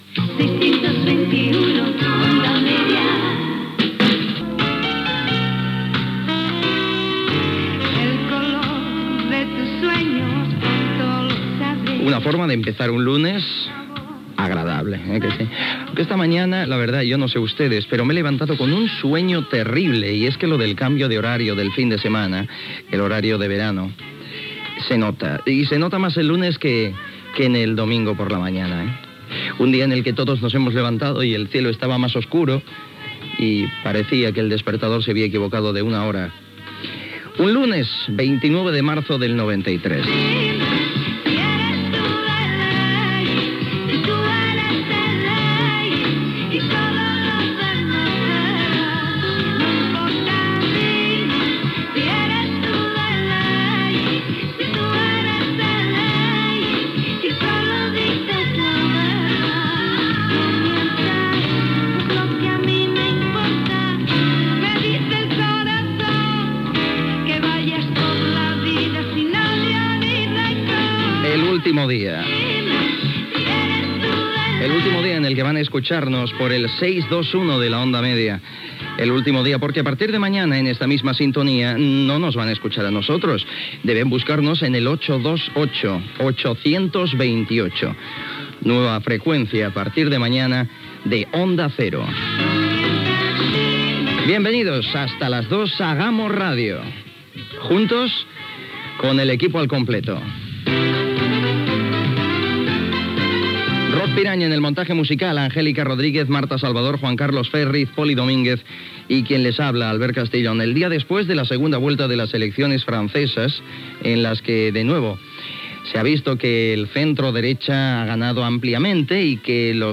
Indicatiu amb la freqüència de l'emissora, comenatri sobre el canvi d'horari, data, avís de la nova freqüència d'emissió a l'endemà, repàs a l'actualitat: eleccions franceses
Info-entreteniment